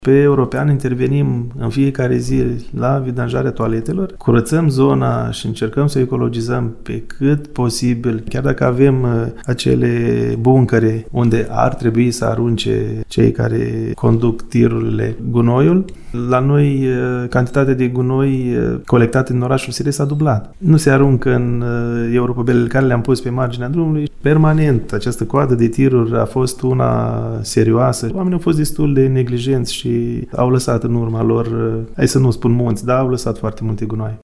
Primarul ADRIAN POPOIU a declarat, pentru Radio VIVA FM, că această problemă rămâne una stringentă, care necesită fonduri și forță de muncă pentru a fi rezolvată.